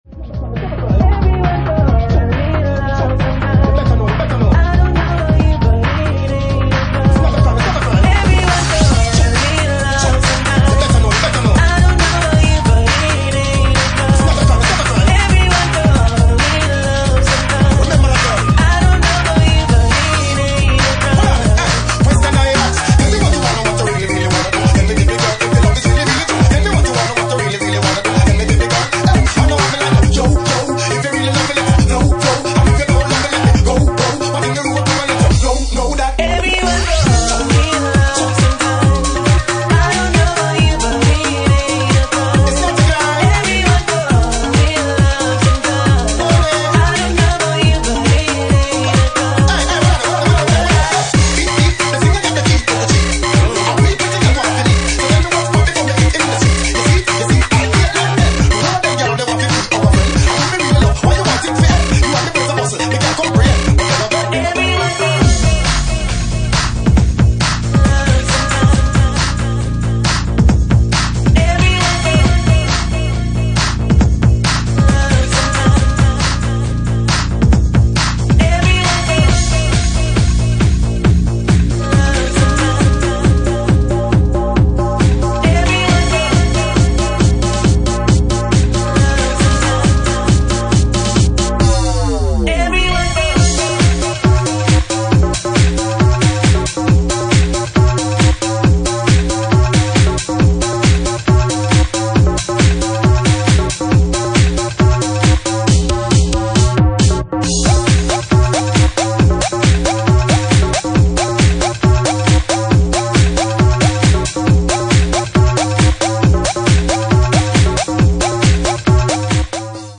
Genre:Bassline House
Bassline House at 69 bpm